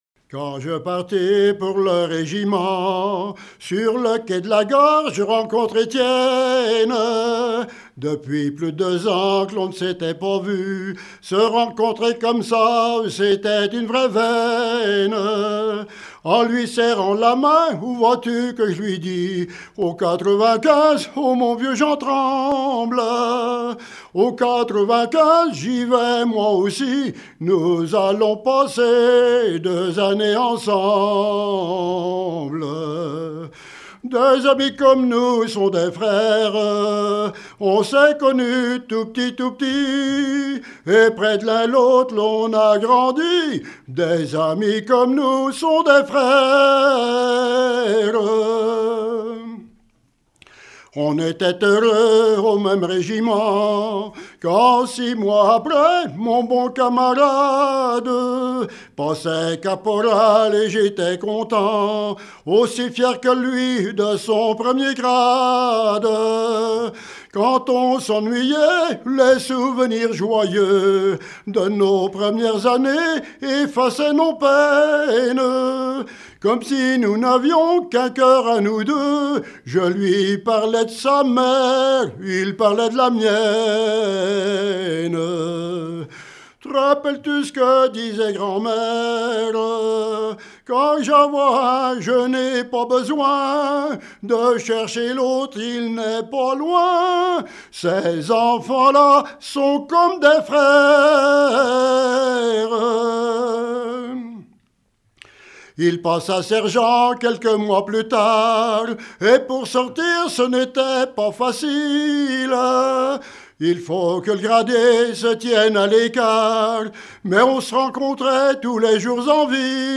Genre strophique
Regroupement de chanteurs du canton
Pièce musicale inédite